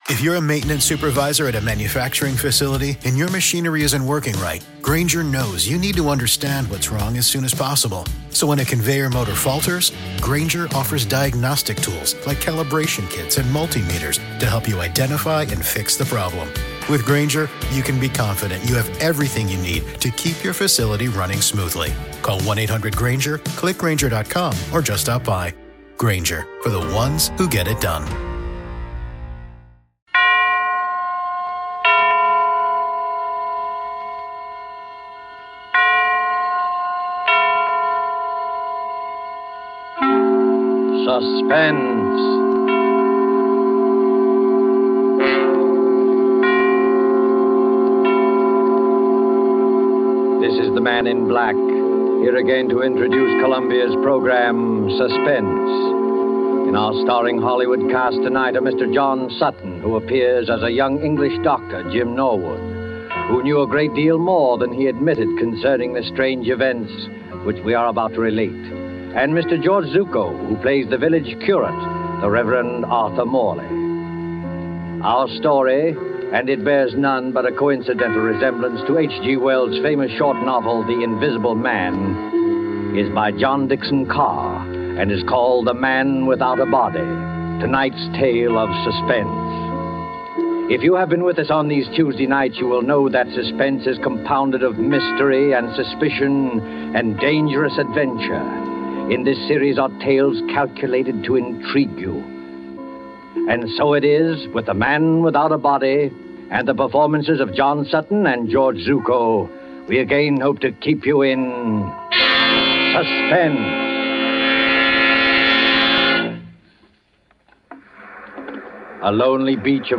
On this episode of the Old Time Radiocast we present you with a double feature of the classic radio program Suspense!